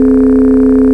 You can make endlessly droning oscillators a well - here are some examples to download and play with: